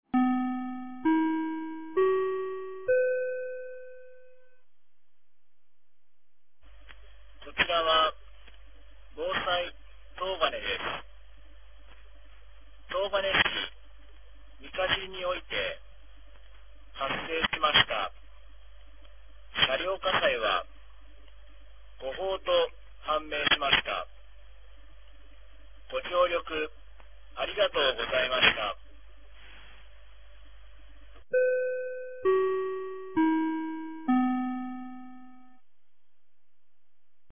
2025年01月27日 15時16分に、東金市より防災行政無線の放送を行いました。
放送音声